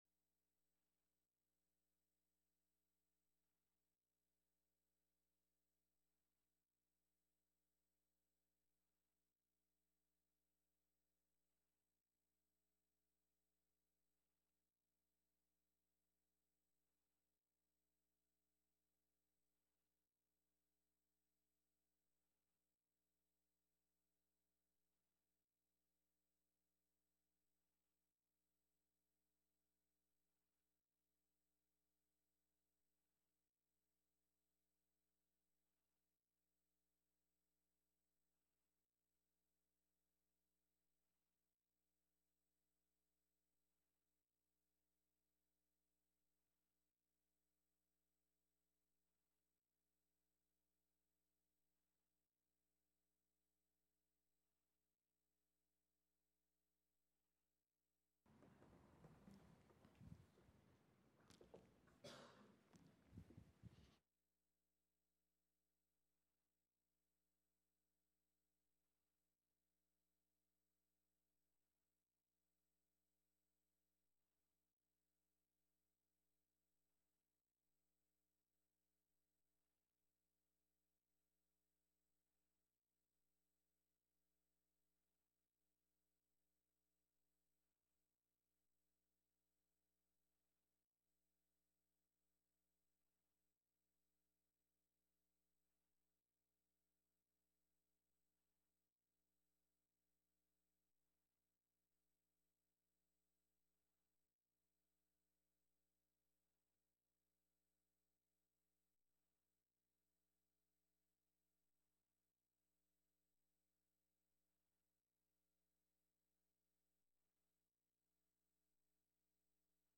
Mark 4:26-28 Worship Service Video November 12 Audio of Message Download Files Bulletin « Kingdom